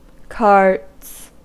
Ääntäminen
Ääntäminen US Haettu sana löytyi näillä lähdekielillä: englanti Käännöksiä ei löytynyt valitulle kohdekielelle. Carts on sanan cart monikko.